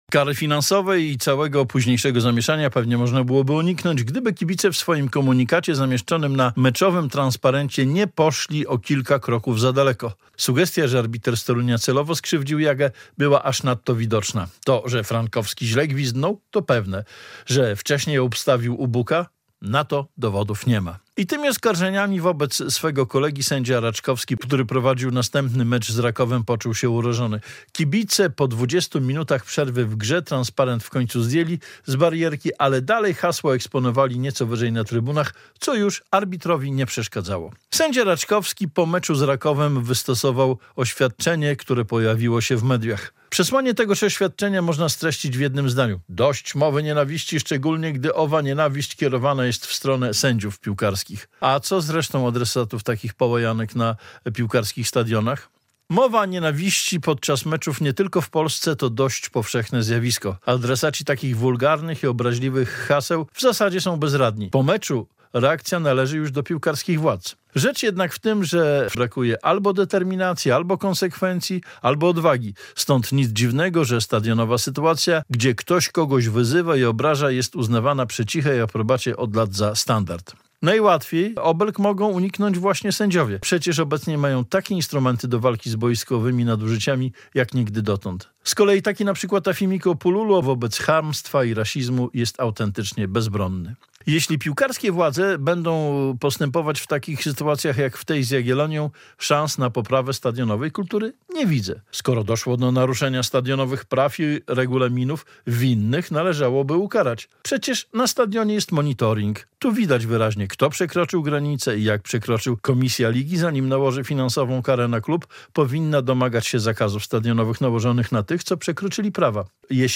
Radio Białystok | Felieton